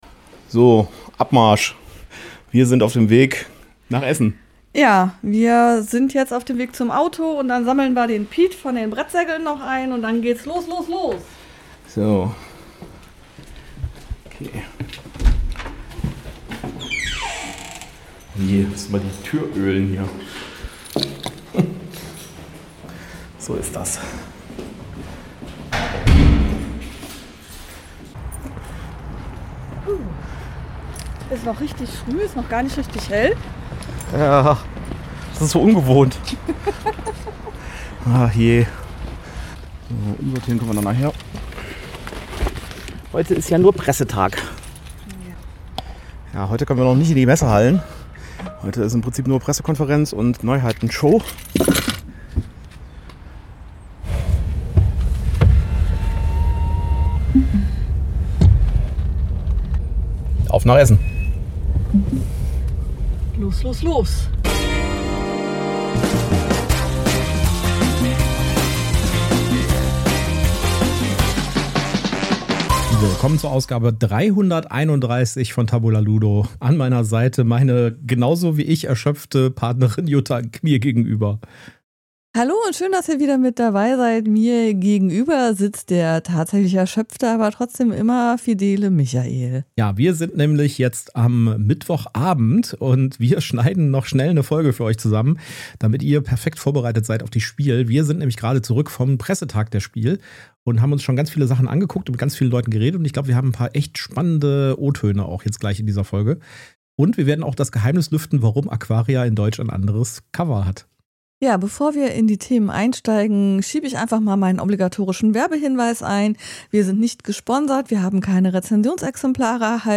Beschreibung vor 6 Monaten Wir waren heute auf dem Pressetag der SPIEL 2025 und haben uns schon einige Neuheiten anschauen und mit den Autoren und Verlagen sprechen können.